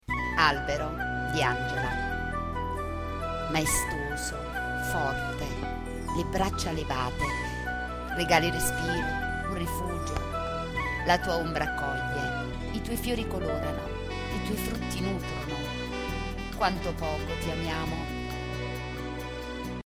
Poesie recitate da docenti